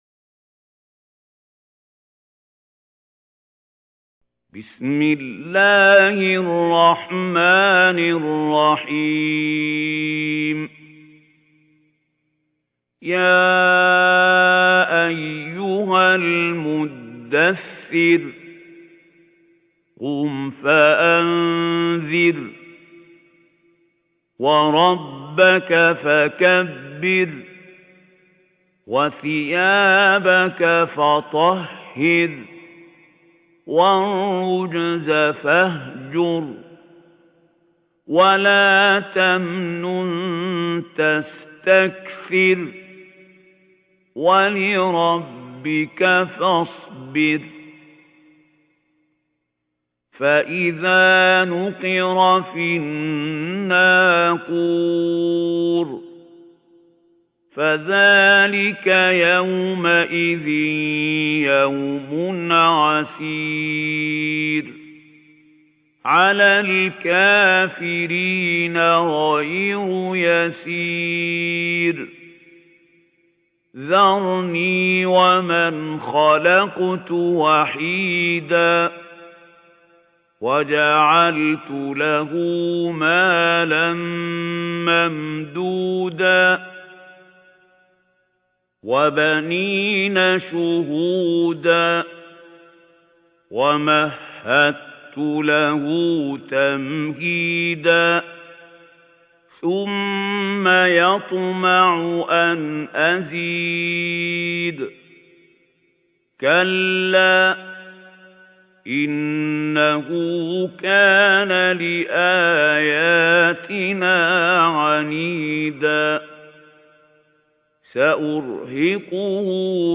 محمود خليل الحصري - بتوسط المد المنفصل